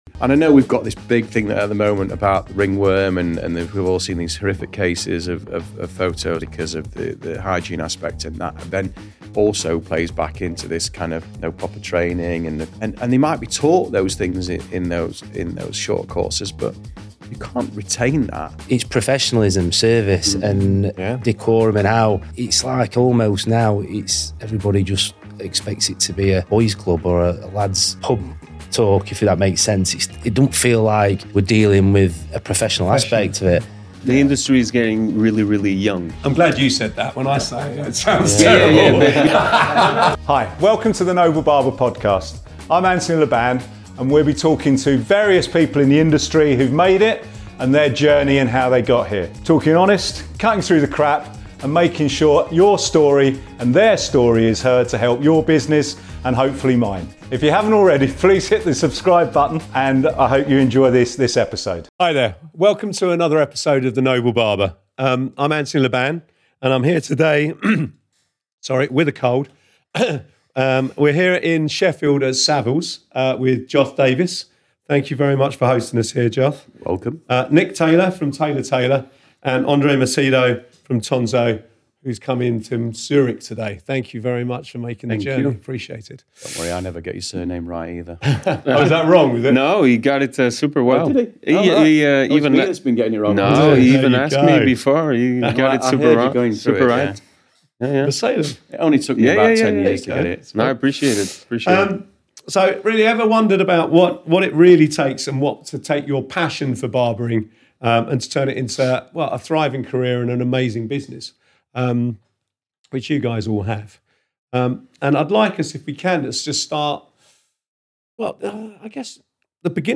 Joined weekly by industry professionals to talk about barbering, becoming and maintaining a barber shop, hiring, employment, money and cutting through the crap of the industry.